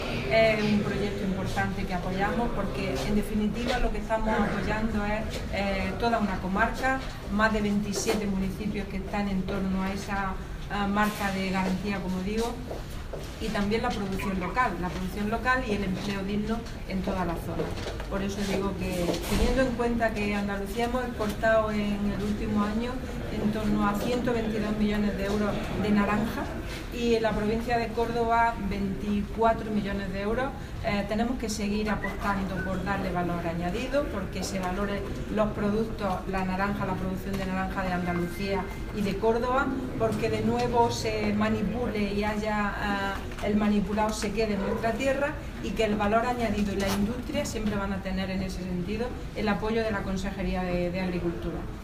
Declaraciones de Carmen Ortiz sobre zumo amparado por la marca de calidad 'Naranjas del Valle del Guadalquivir' 1